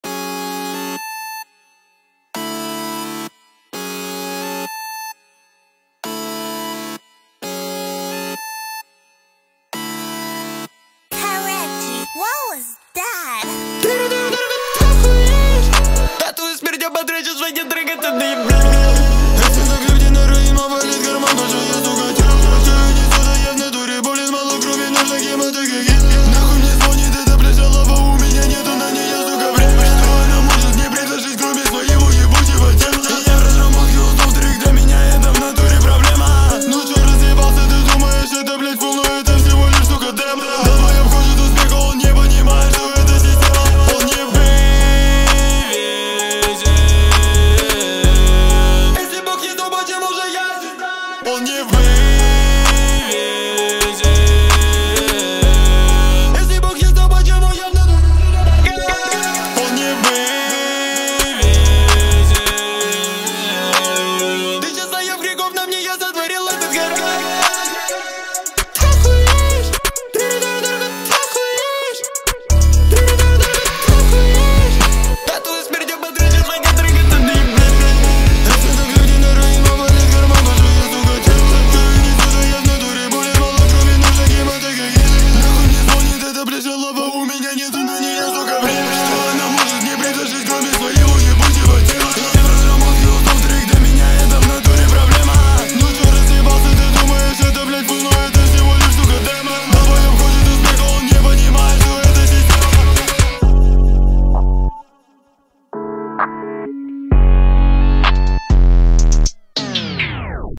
Русские песни
• Качество: 320 kbps, Stereo